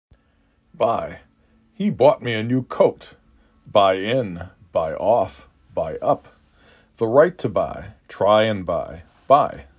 3 Letters, 1 Syllable
b I